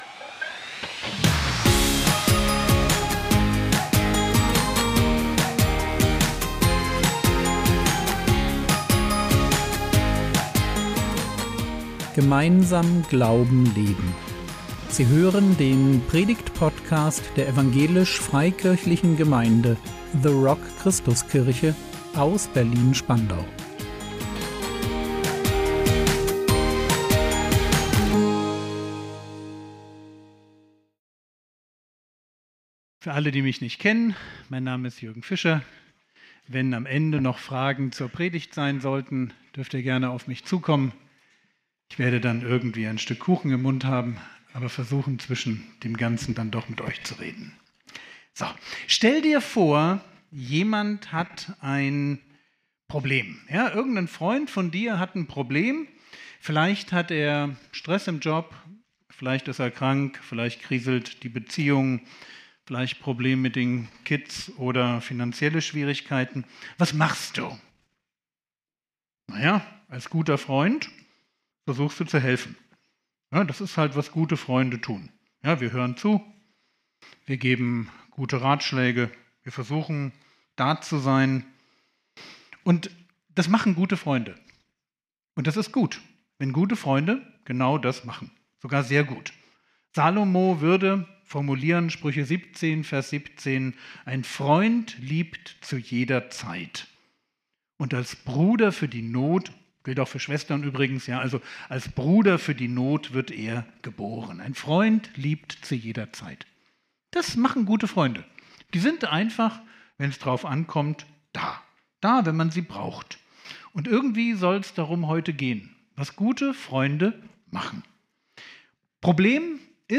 Wenn Jesus tiefer blickt | 22.03.2026 ~ Predigt Podcast der EFG The Rock Christuskirche Berlin Podcast